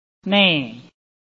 拼音查詢：【海陸腔】ne ~請點選不同聲調拼音聽聽看!(例字漢字部分屬參考性質)